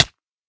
hit1.ogg